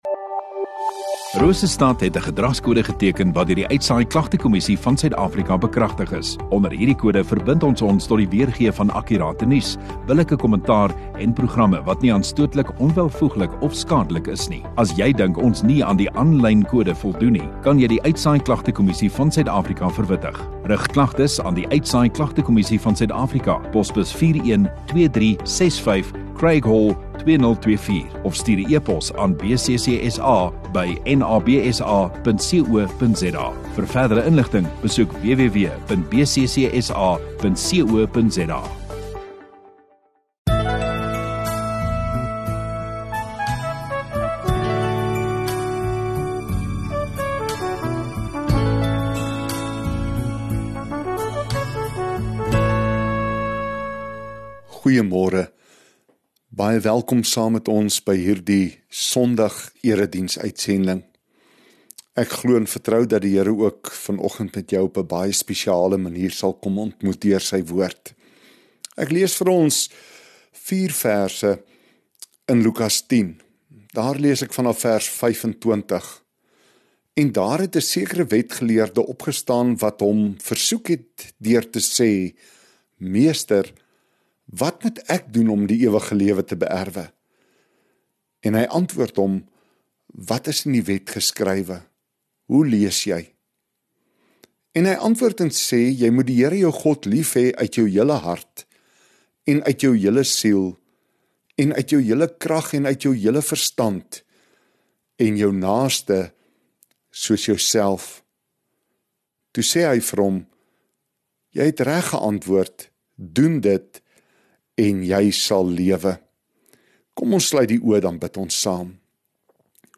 27 Jul Sondagoggend Erediens